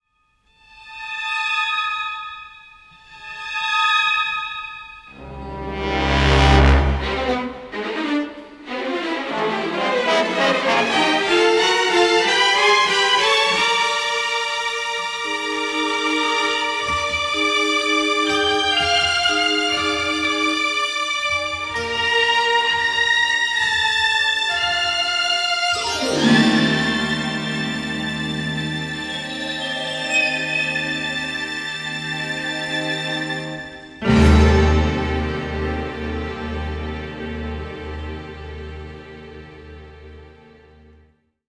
tense suspense music